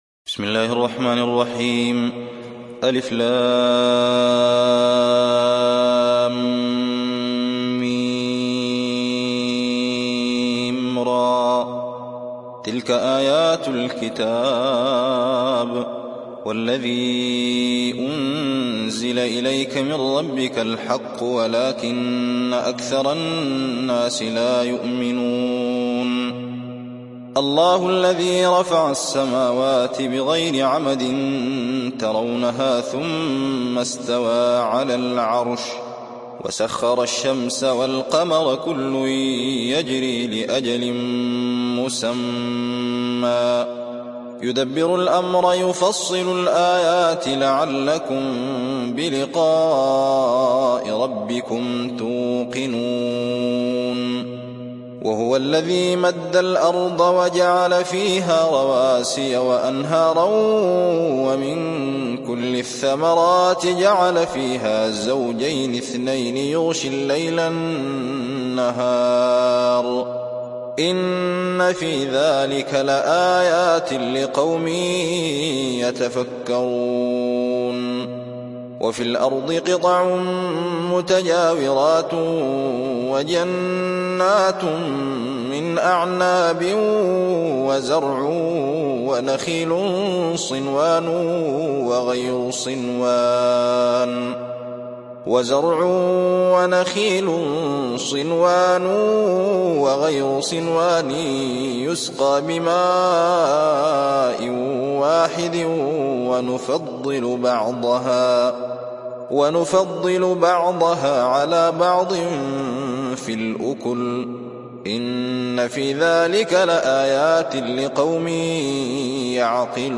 Riwayat Hafs an Asim